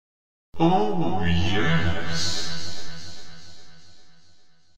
OOOOH YES Effect Button OOOOH YES Effect Downlaod OOOOH YES Effect Sound Download Download for iphone
oooooh-yesssss.mp3